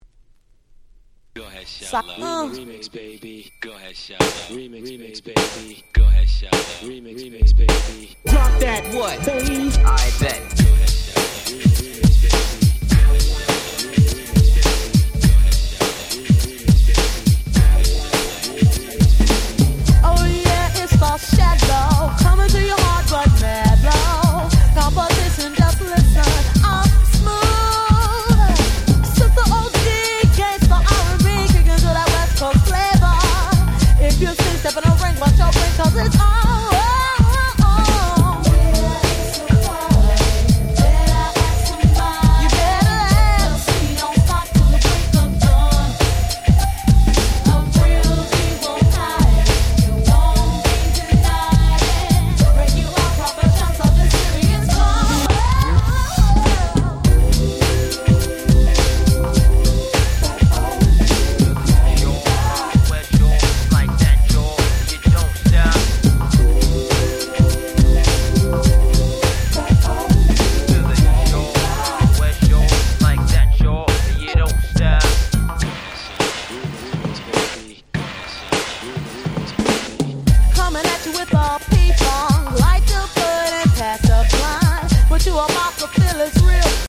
94' Nice West Coast R&B !!